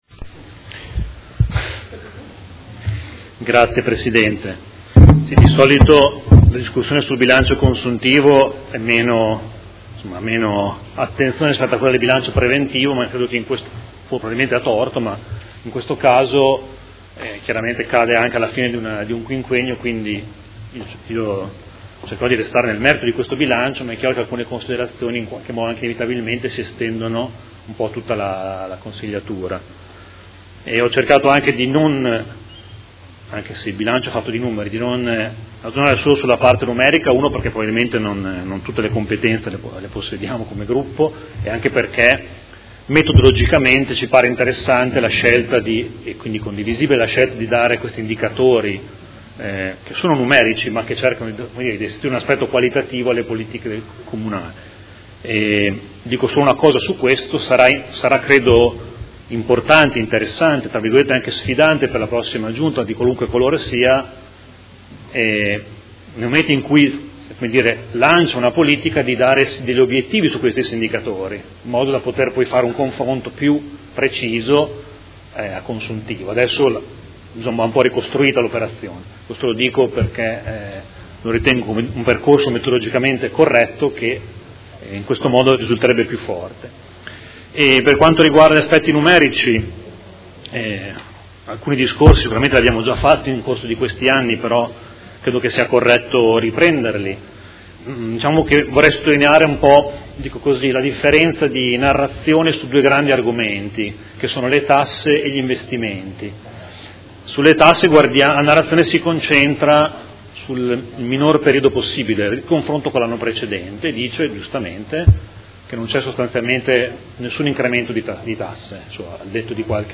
Seduta del 29/04/2019. Dibattito su proposta di deliberazione: Rendiconto della Gestione del Comune di Modena per l’Esercizio 2018 - Approvazione